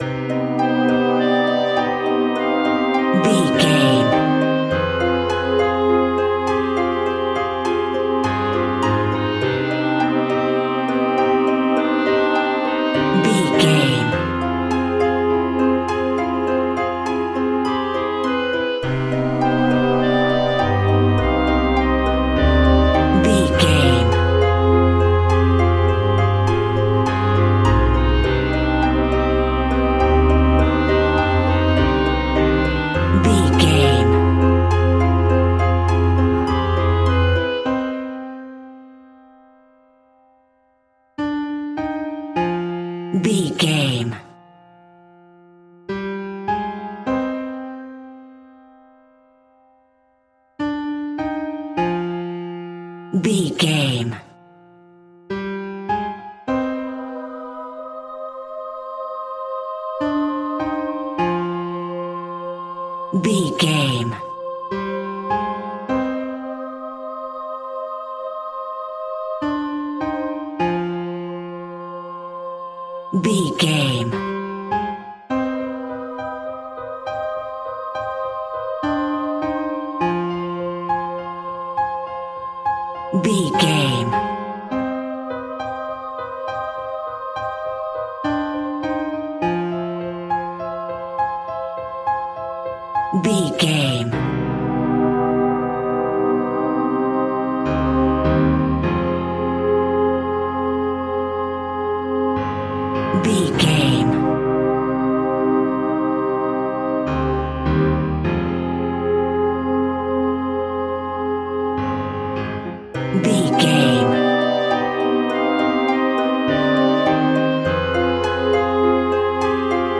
Aeolian/Minor
scary
tension
ominous
dark
suspense
eerie
harp
strings
flute
drums
horror
synth
pads